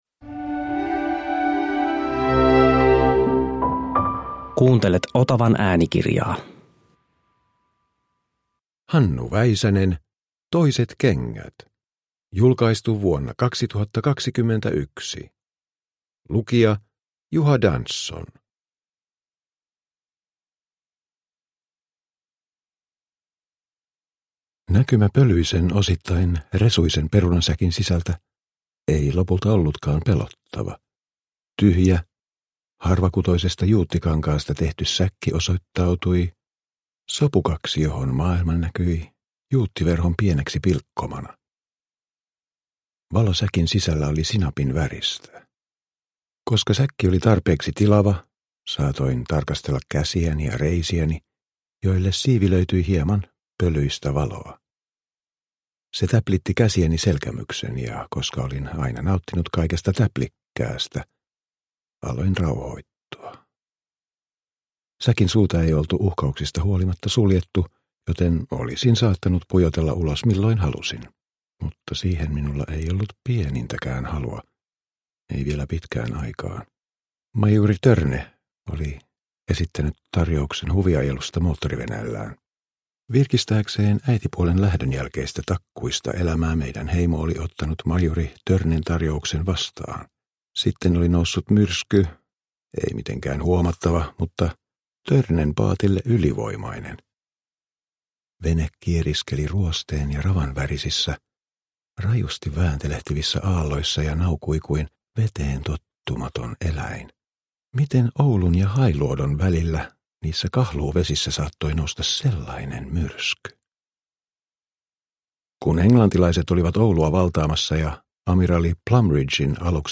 Toiset kengät – Ljudbok – Laddas ner